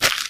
STEPS Newspaper, Run 10.wav